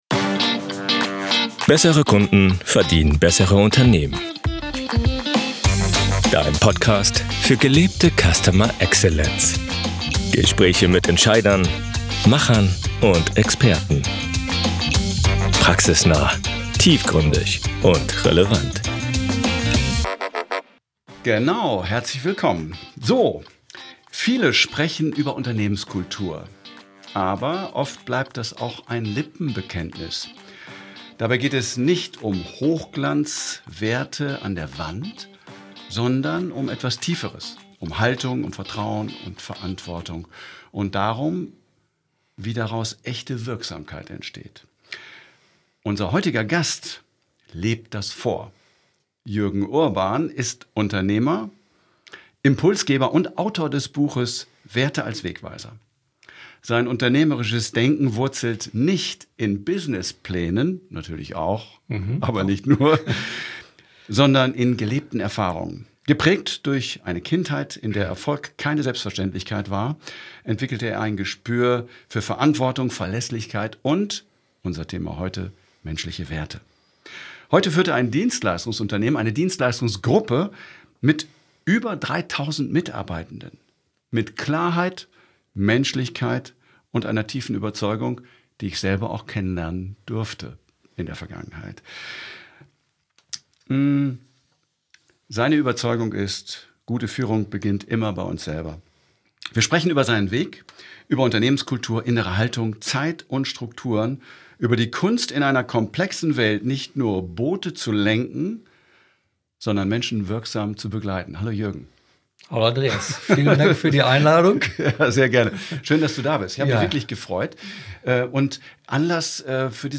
Im Gespräch geht es unter anderem um: die Frage, wie Werte zu echten Erfolgsfaktoren werden die Rolle von Vertrauen in der Führung Selbstführung und gesunde Strukturen die Grenzen von Selbstoptimierung Benefits, Onboarding und echte Verbundenheit die Kunst, Menschen wirksam zu begleiten – statt nur Boote zu lenken